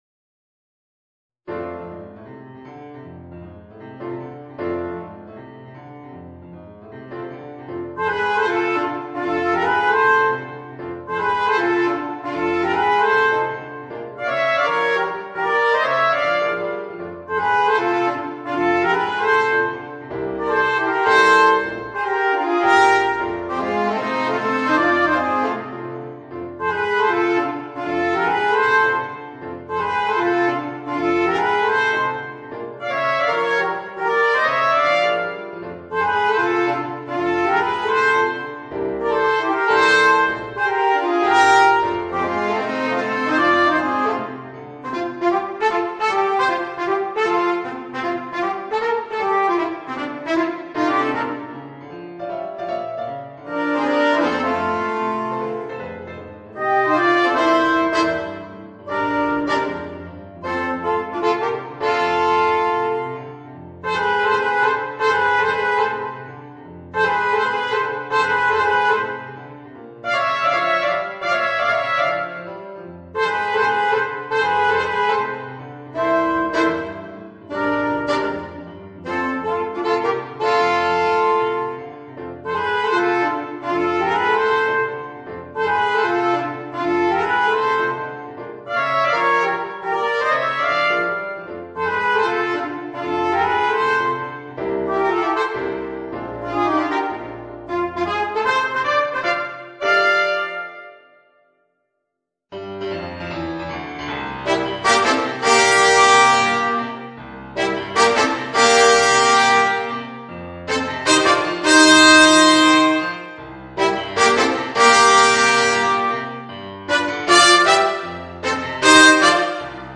Voicing: Alto Saxophone, Trumpet w/ Audio